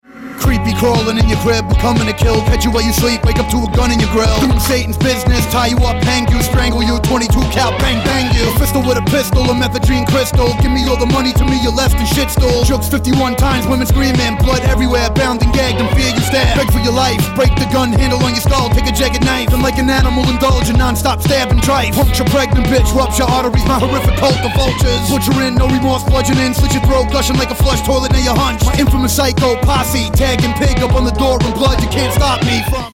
• Качество: 128, Stereo
мужской голос
Хип-хоп
Rap
речитатив